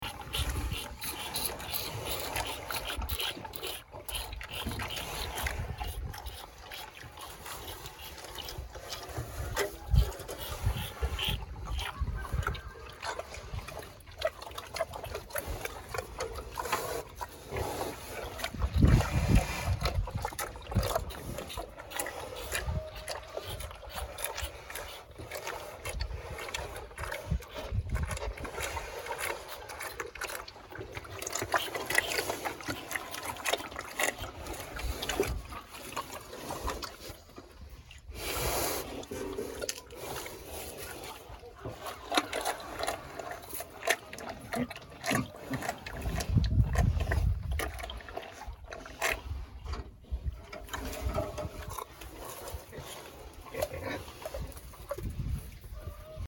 Звуки свиньи